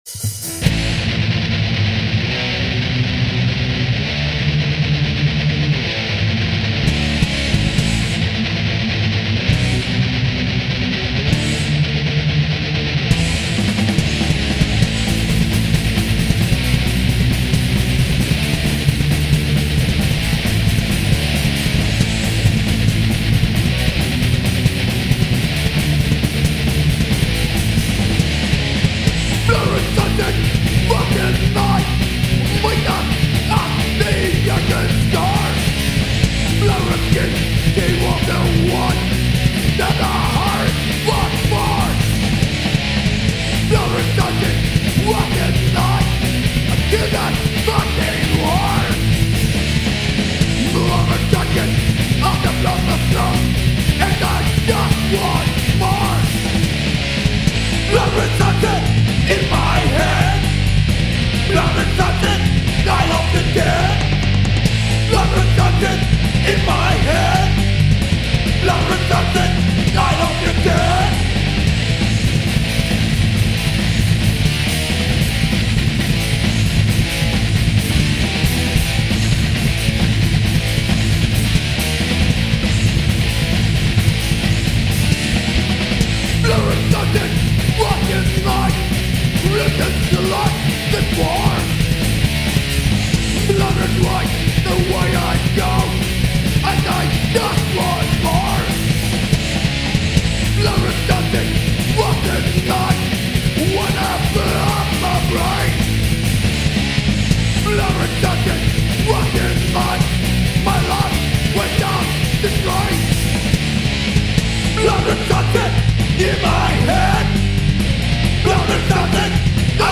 Metalcore